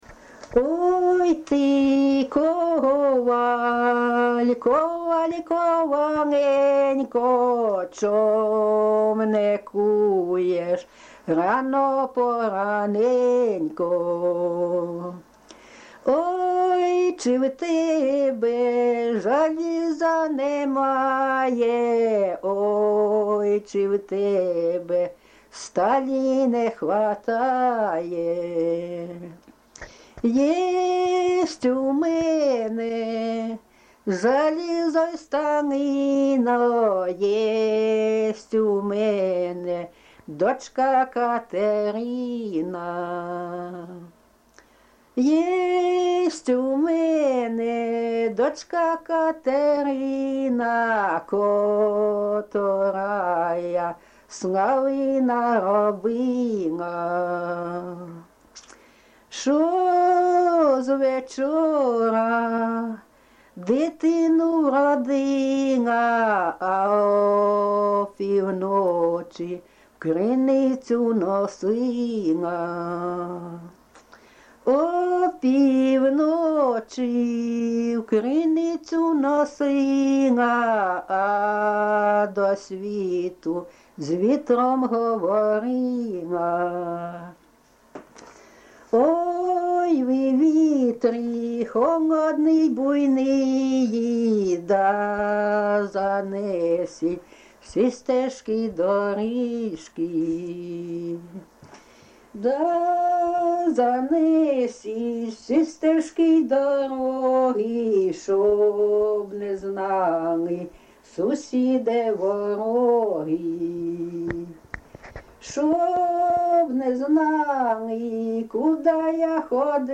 ЖанрПісні з особистого та родинного життя, Балади
Місце записус. Курахівка, Покровський район, Донецька обл., Україна, Слобожанщина